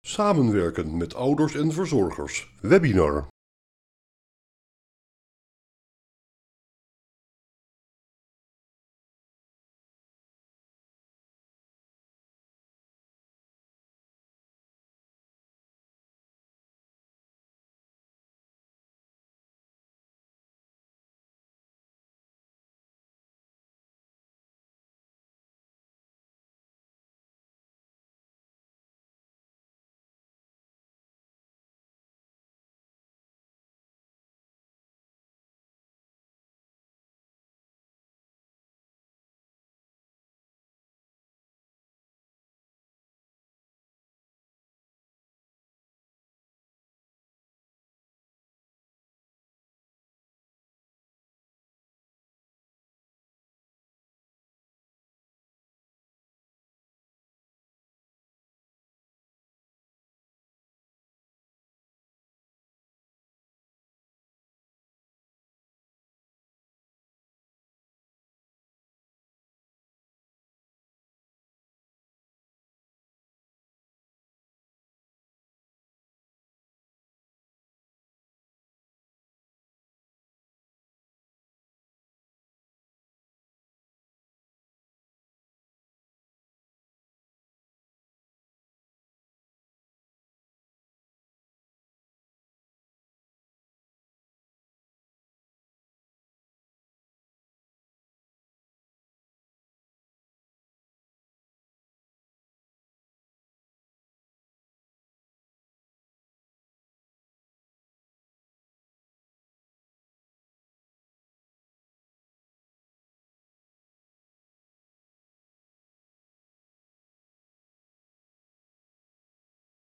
Terugkijken: Webinar 'Samenwerken met Ouders en Verzorgers' | Nationaal Programma Onderwijs